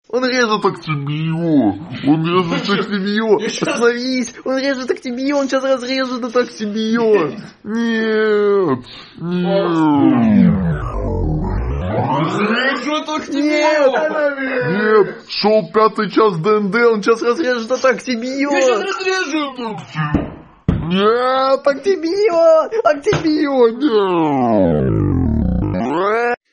Pad Sound Effects MP3 Download Free - Quick Sounds